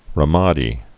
(rə-mädē)